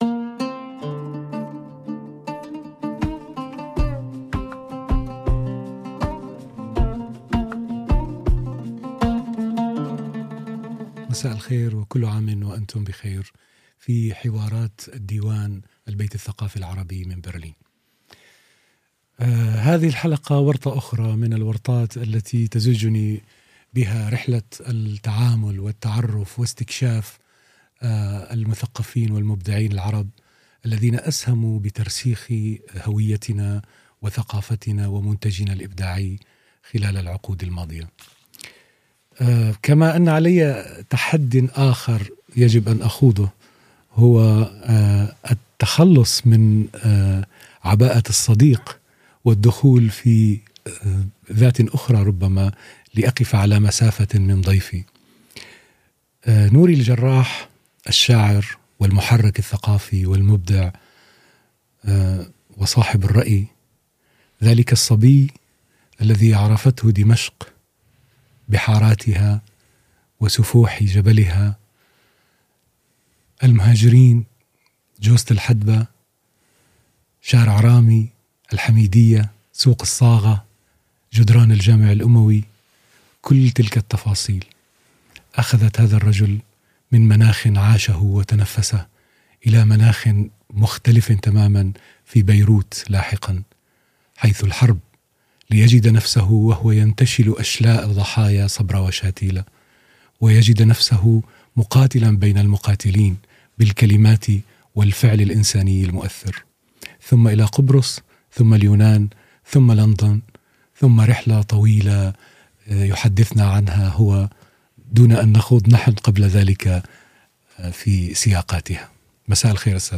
[AR] (1/2) Im Gespräch mit Nouri Al-Jarrah حوار الديوان مع نوري الجرّاح ~ DIVAN Podcasts Podcast